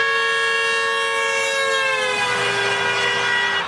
LP18 Son klaxon effet Doppler.mp3